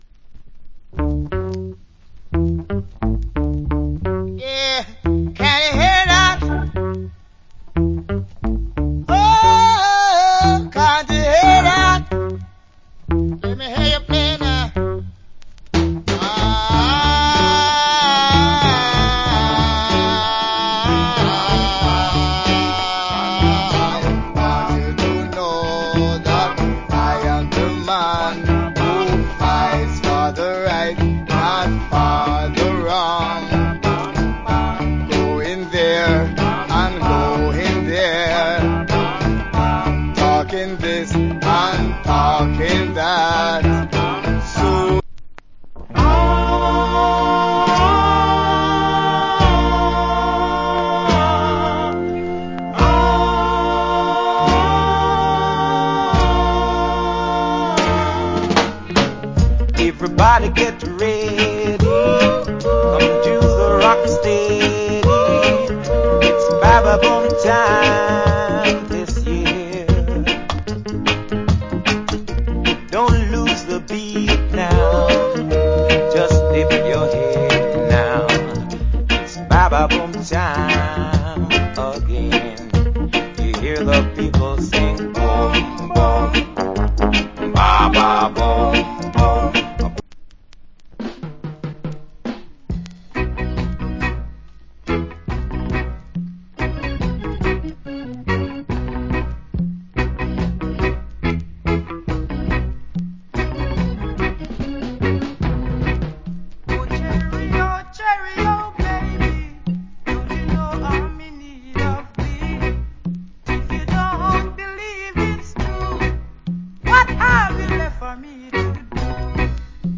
Nice Rock Steady To Reggae.